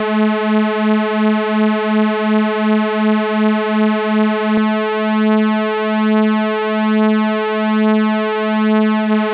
DETUNE 2.wav